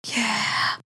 Category 🗣 Voices
american-english female-voice girl sexy speak speech talk vocal sound effect free sound royalty free Voices